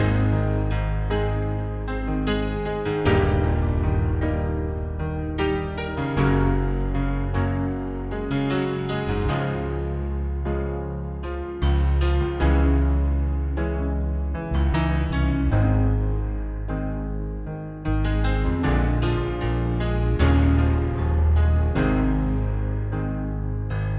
Two Semitones Down Pop (2010s) 3:32 Buy £1.50